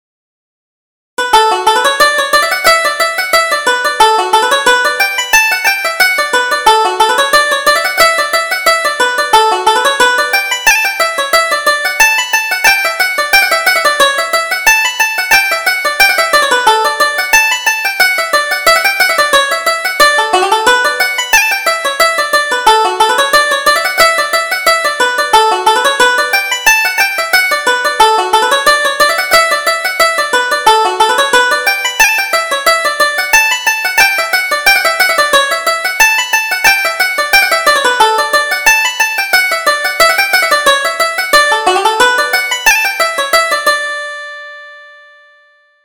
Reel: Miss Campbell